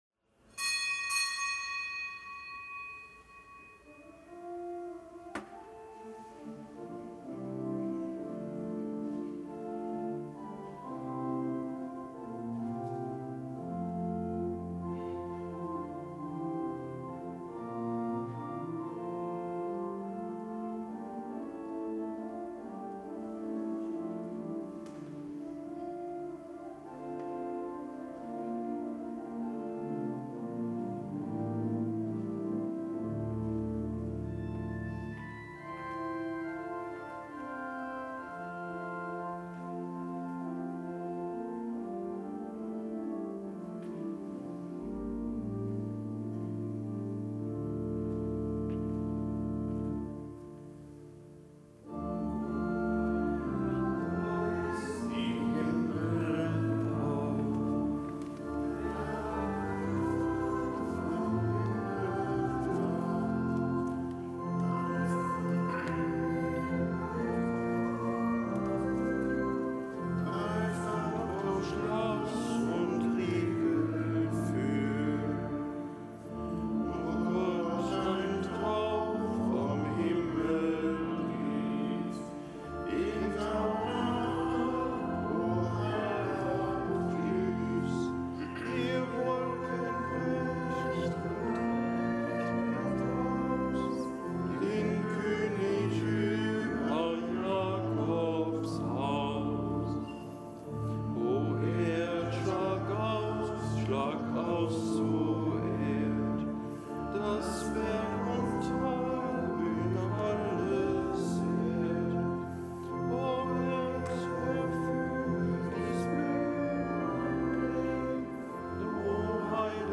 Kapitelsmesse am Dienstag der zweiten Adventswoche
Kapitelsmesse aus dem Kölner Dom am Dienstag der zweiten Adventswoche; Nichtgebotener Gedenktag Johannes Didacus (Juan Diego) Cuauhtlatoatzin, Glaubenszeuge; Zelebrant: Weihbischof Dominikus Schwad